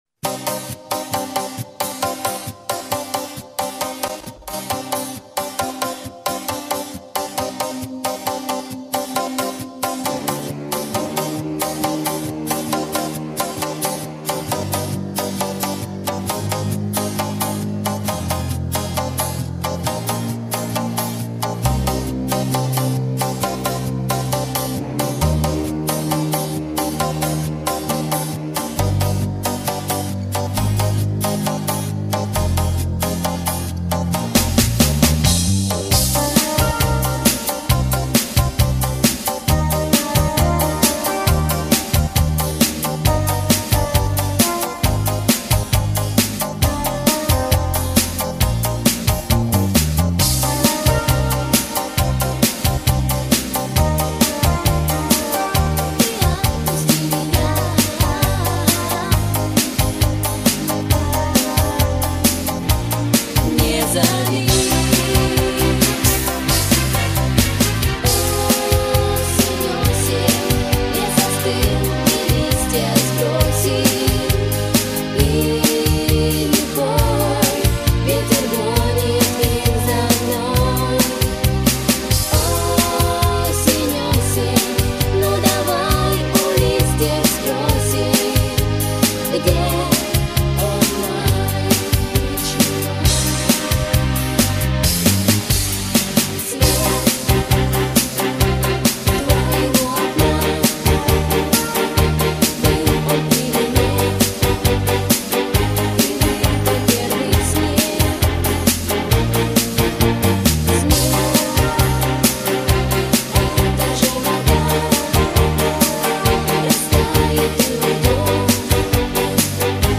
МИНУС+ БЕК